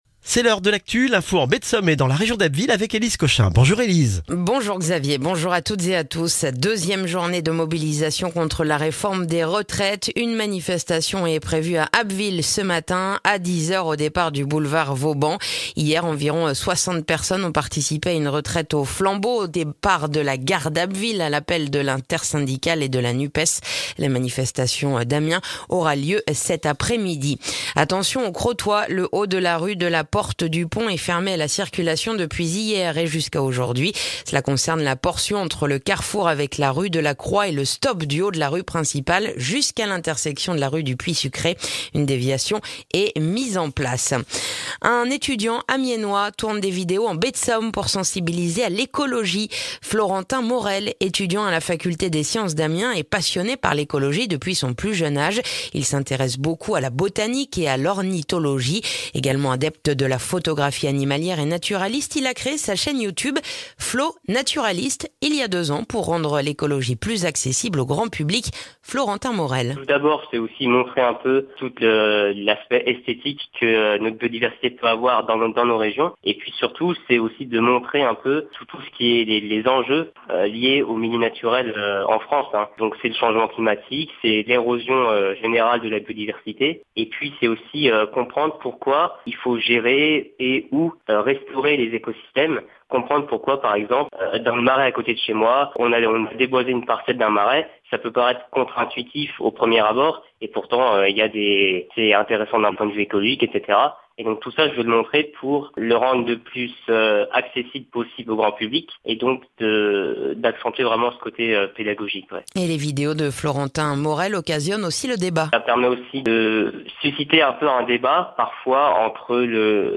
Le journal du mardi 31 janvier en Baie de Somme et dans la région d'Abbeville